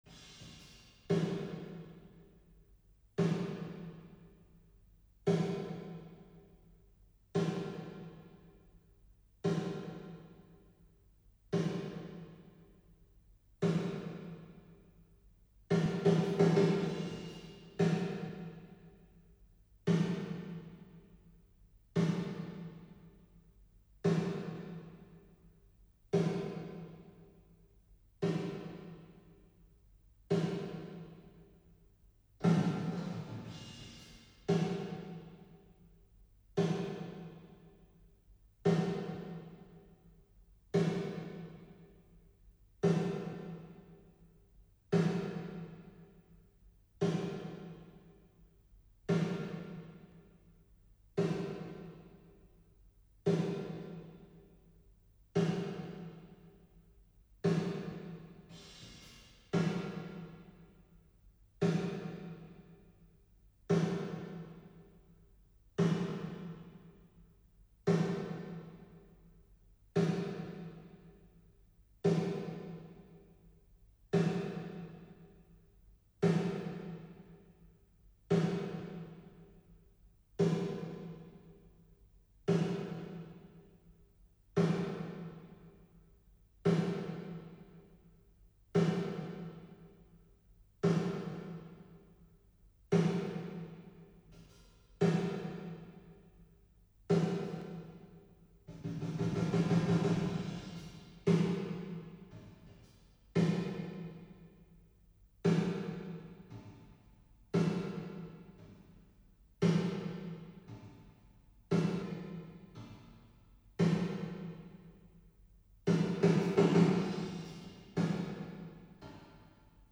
Много-много ревера.
Вот это, например, что за ревер?